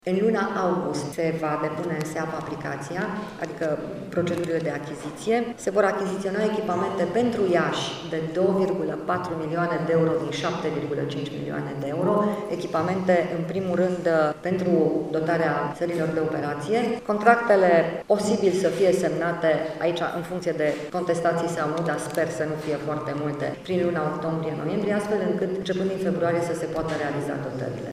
În total, în România, va fi achiziţionată aparatură pentru unităţile de arşi în valoare de 7,5 milioane de euro, a declarat, vineri, la Iaşi, ministrul Sănătăţii, Sorina Pintea: